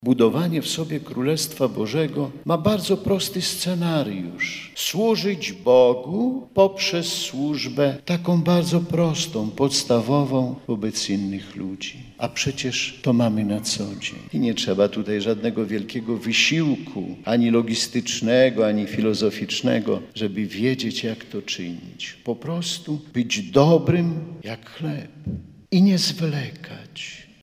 W homilii bp Kamiński podkreślił, że Królestwo Boże ma przede wszystkim charakter duchowy.